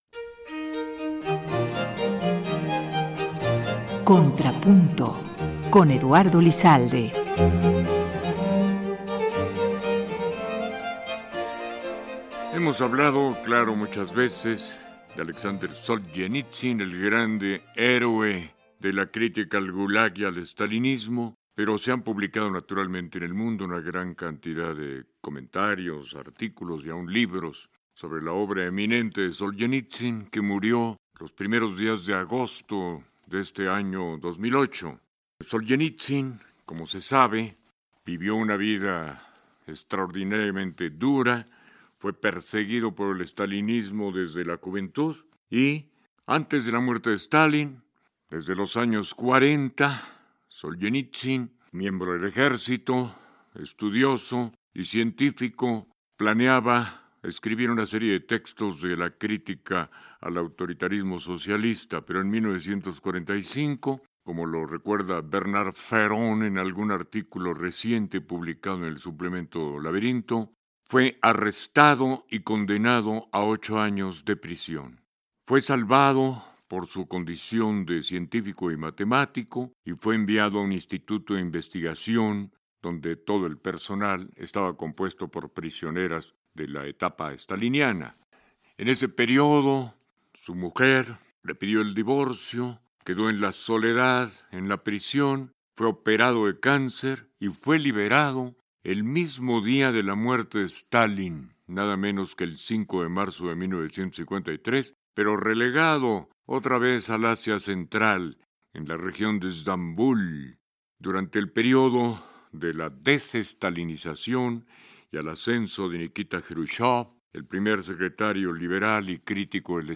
Entre sus obras destacan “Por el bien de la causa”, “El primer círculo”, “Lenin de Zurich” y “Cómo reorganizar Rusia” Escucha unos comentarios sobre Solzhenitsyn en el programa de Eduardo Lizalde, “Contrapunto”, transmitido en 2008.